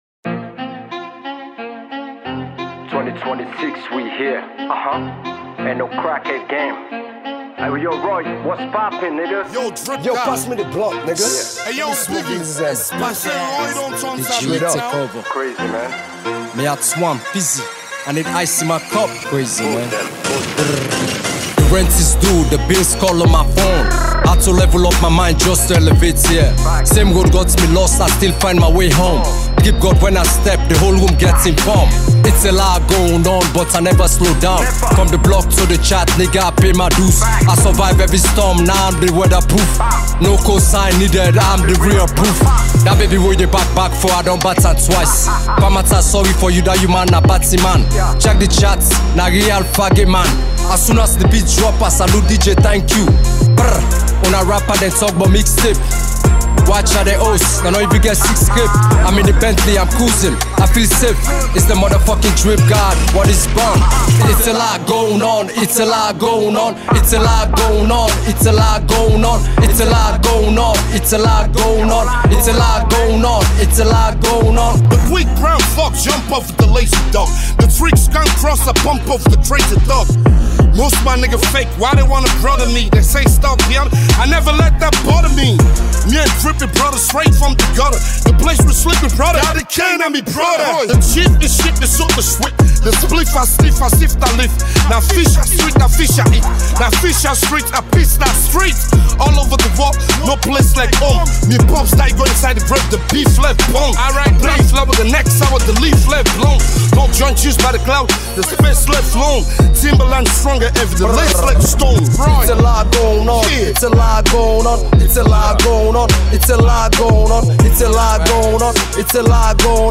With hardcore beats
blends sharp lyrics with a futuristic vibe